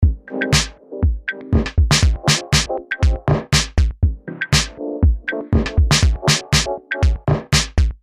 描述：3个midi轨道，鼓，贝斯，罗得斯钢琴在逻辑中记录。
标签： 120 bpm Jazz Loops Groove Loops 1.35 MB wav Key : Unknown
声道立体声